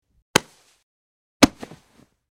Cloth, Grab
Body Pats And Grabs With Cloth Movement, X2